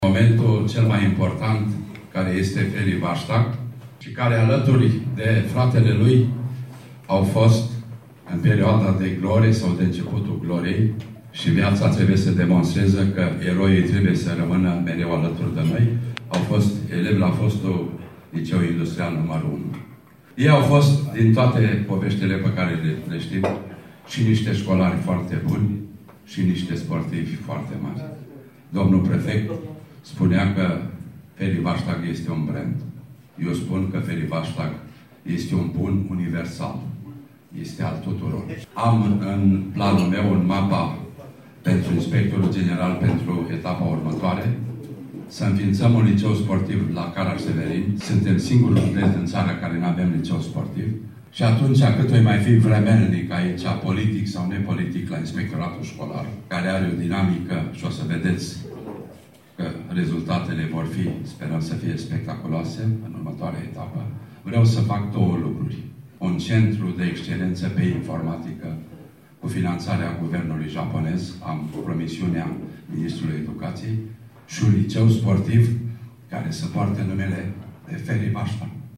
Inspectorul Școlar Județean din Caraș-Severin, prof. Ioan Benga a făcut anunțul la festivitatea de acordare a titlului de cetățean de onoare al comunei Fârliug, multiplului campion mondial, Francisc Vaștag, cel care a împlinit 49 de ani, duminică.